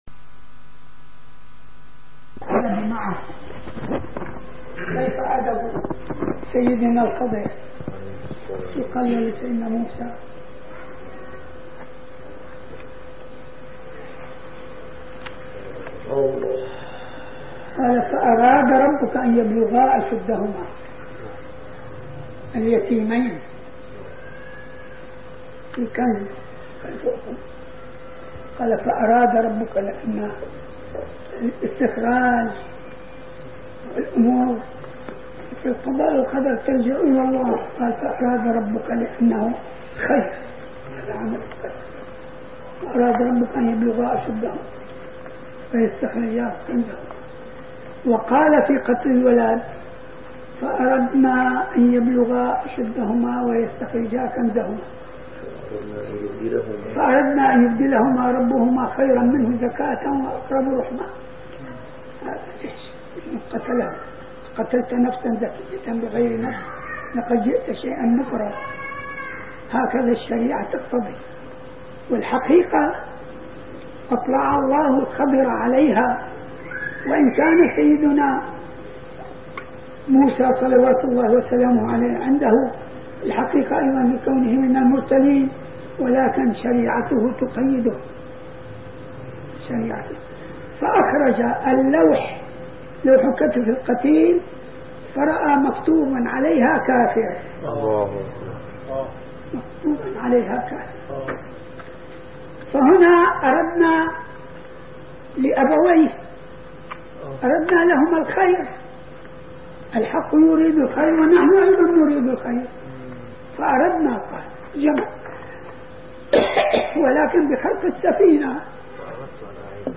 - الدروس العلمية - شرح كتاب مفتاح الجنة والوظيفة الشاذلية - الدرس الثاني: الوظيفة الشاذلية